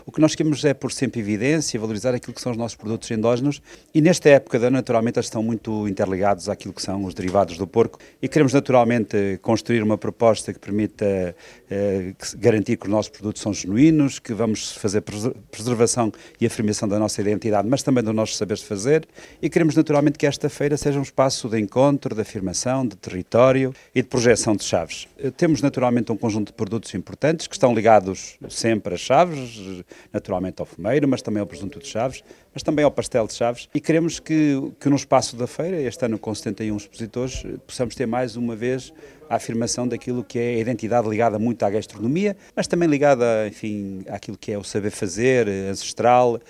O presidente da Câmara Municipal de Chaves, Nuno Vaz, sublinha que o principal objetivo do evento é a valorização da identidade gastronómica do território: